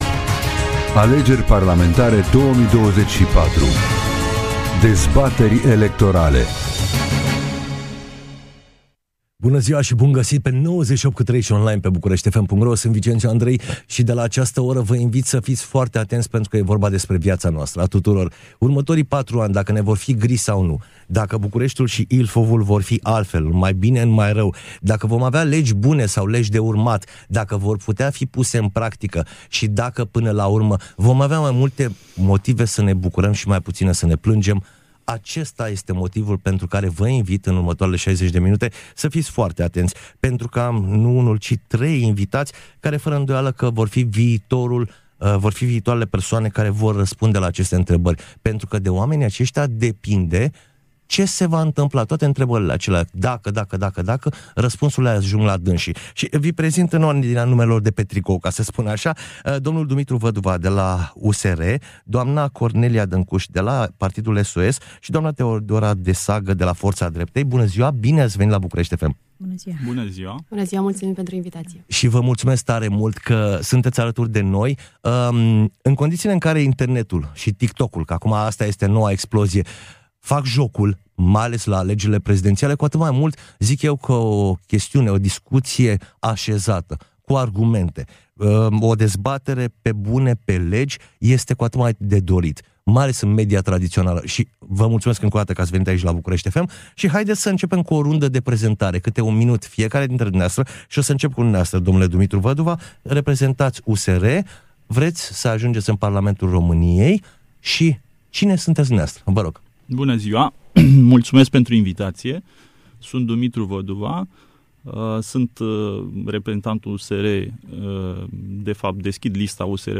dezbatere electorala